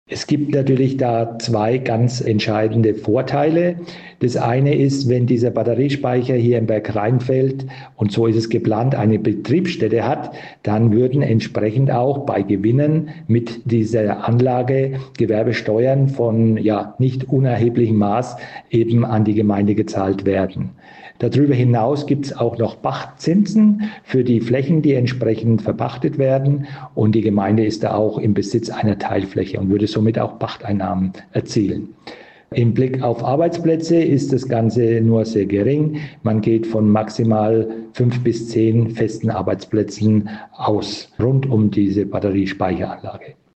Wir sprechen mit Ulrich Werner, dem Bergrheinfelder Bürgermeister. Was hätte die Gemeinde davon?